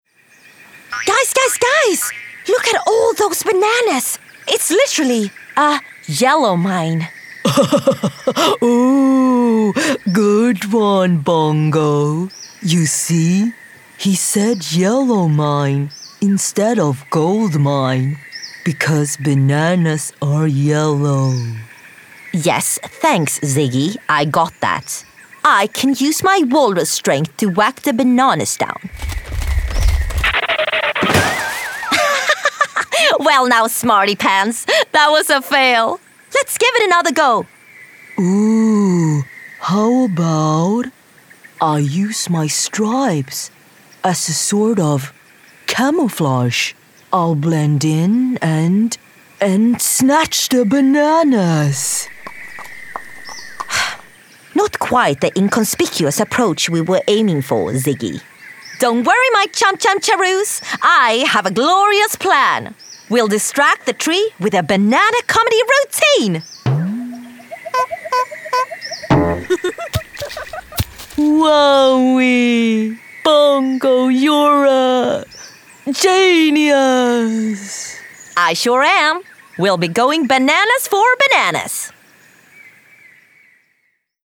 Voice Reel
Animation